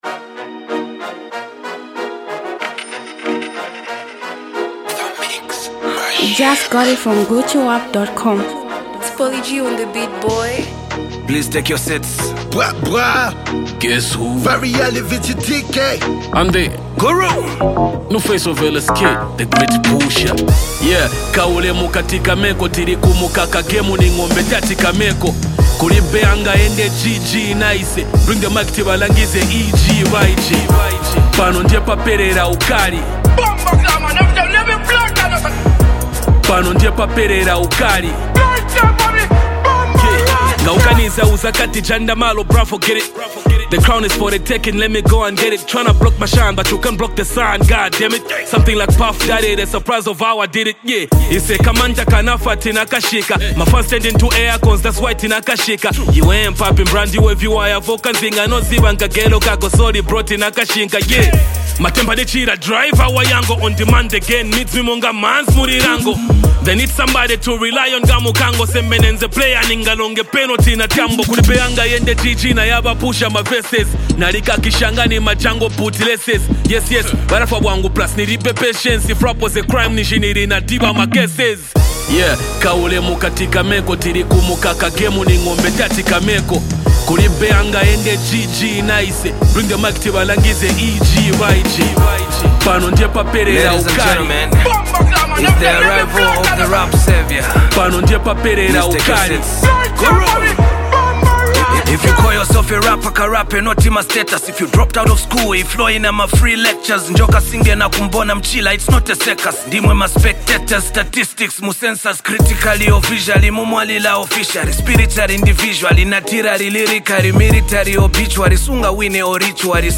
street anthem
hip-hop
heavyweight rapper and songwriter.